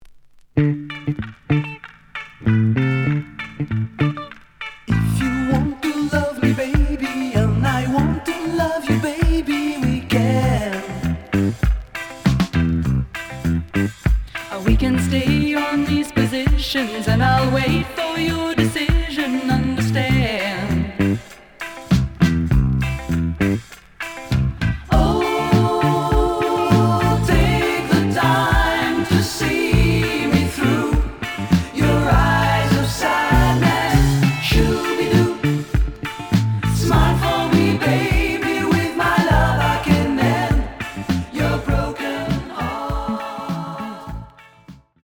(Mono)
試聴は実際のレコードから録音しています。
●Genre: Disco
EX-, VG+ → 傷、ノイズが多少あるが、おおむね良い。